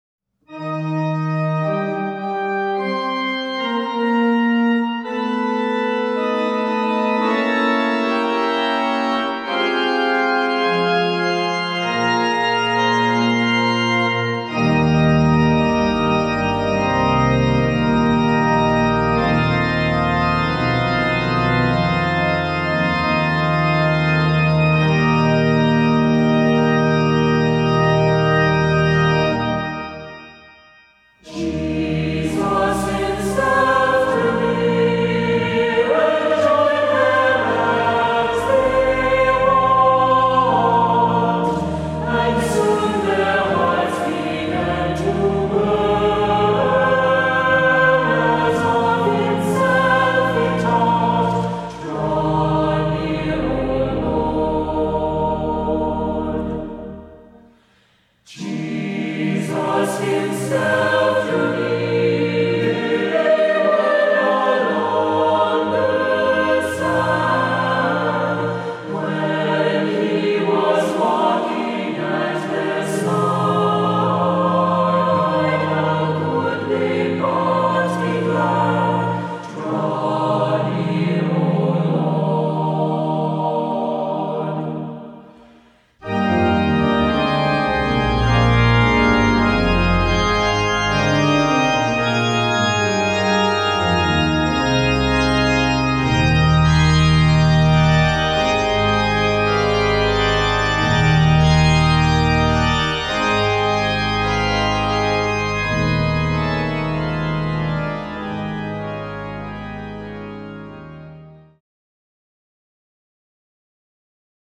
Voicing: SATB,Assembly